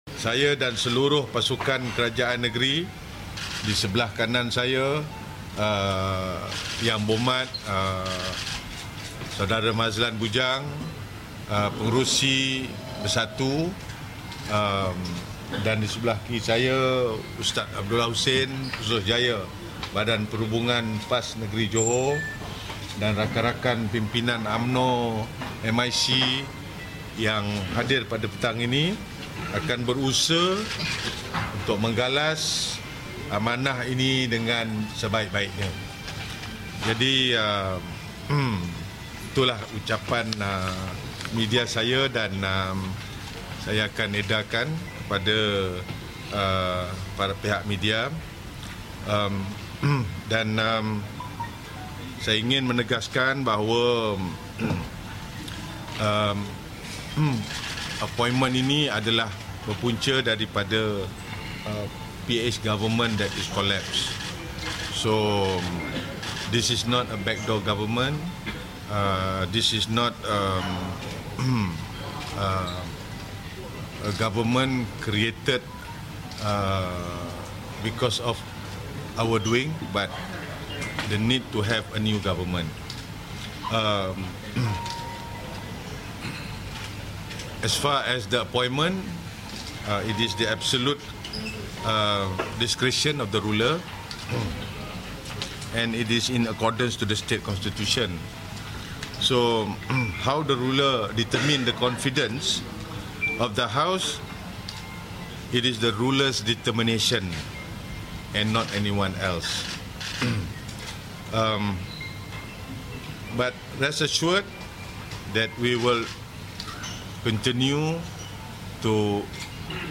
[SIDANG MEDIA] Malaysia2020: Hasni Mohammad angkat sumpah MB Johor